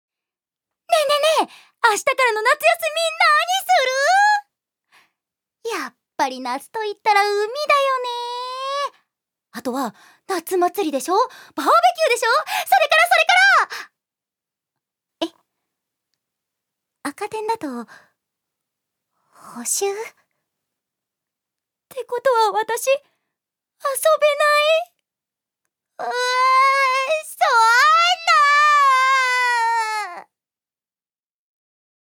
女性タレント
セリフ１